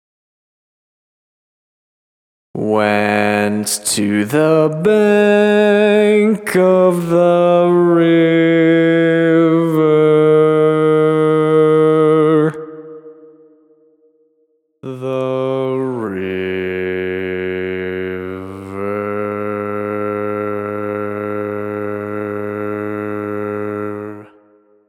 Key written in: A♭ Major
Type: Barbershop
Each recording below is single part only.